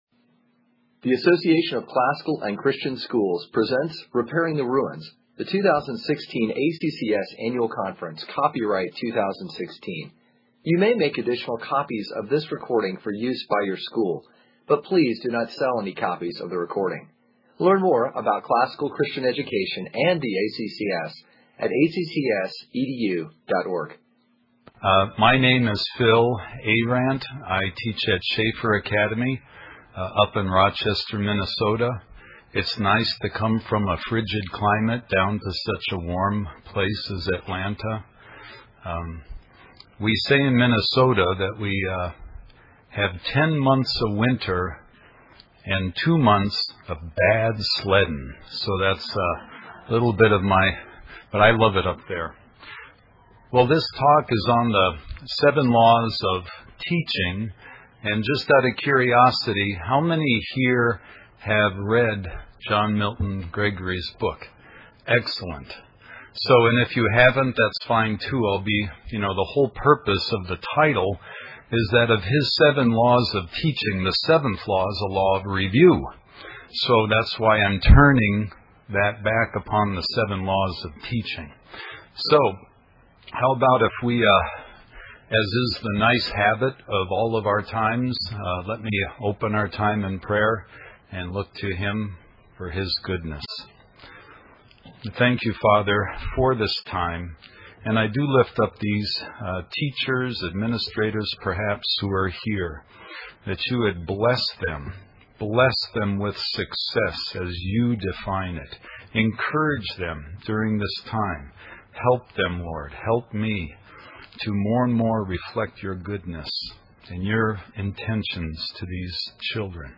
2016 Workshop Talk | 0:51:44 | All Grade Levels, General Classroom